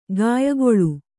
♪ gāyagoḷu